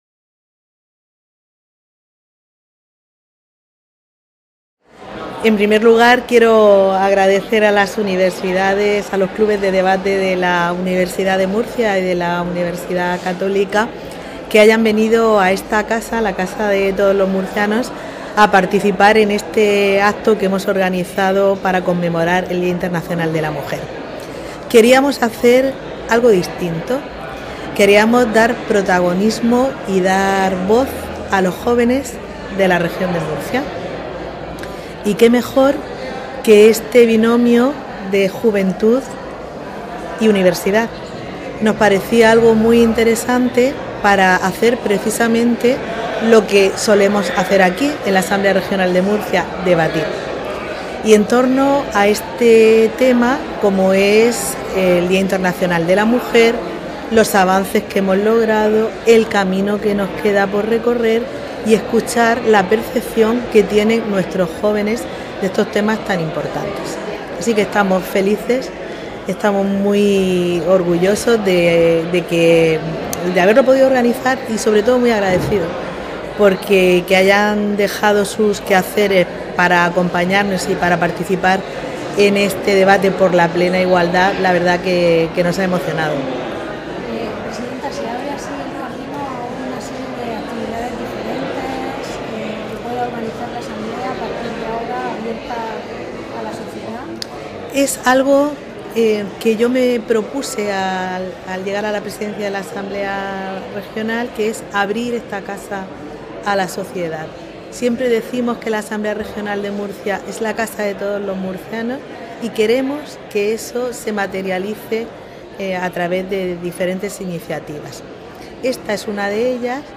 • Declaraciones de la presidenta de la Asamblea Regional, Visitación Martínez